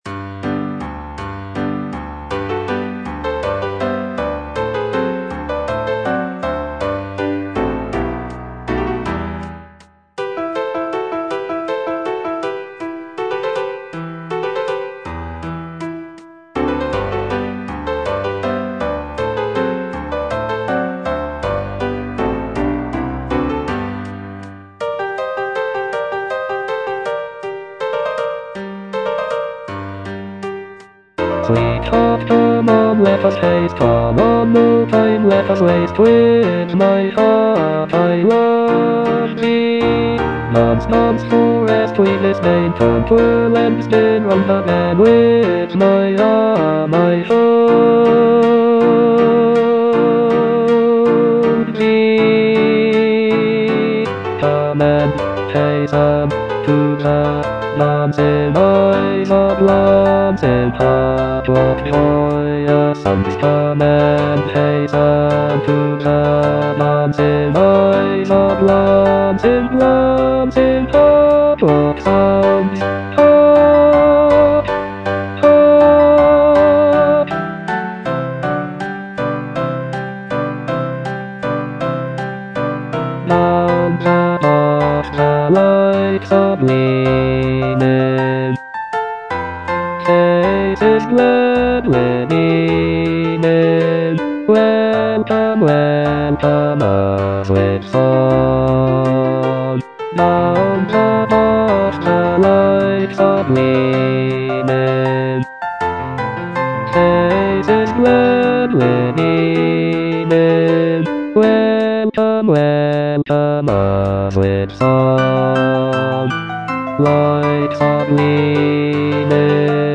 E. ELGAR - FROM THE BAVARIAN HIGHLANDS The dance (bass I) (Voice with metronome) Ads stop: auto-stop